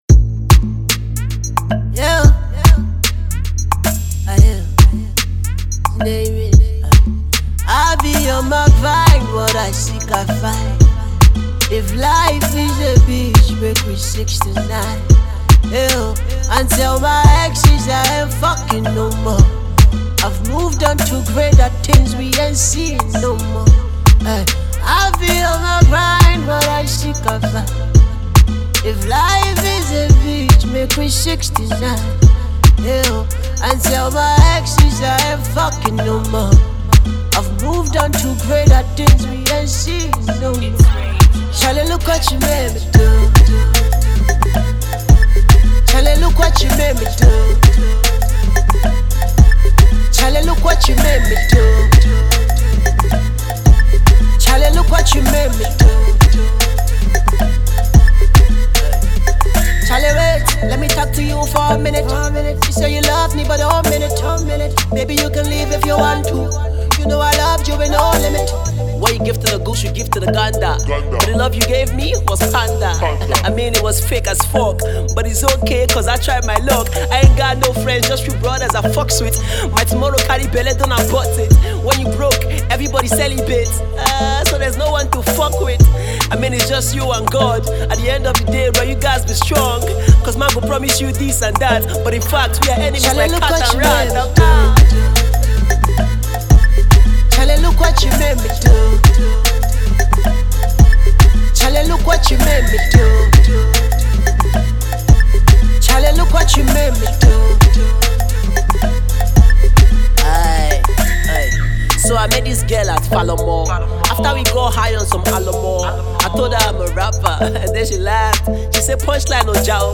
We love him for his tempo and energy.